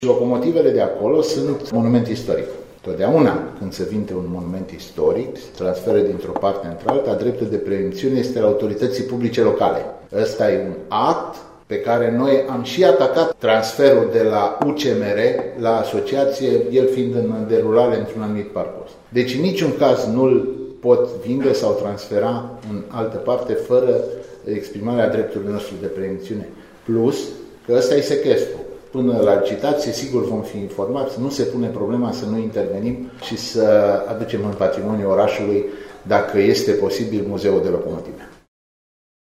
Muzeul de locomotive cu abur din Reșița nu va fi înstrăinat, chiar dacă este pus sub sechestru. Viceprimarul cu atribuţii de primar a menţionat în şedinţa Consiliului local de astăzi că este vorba deocamdată doar de sechestru.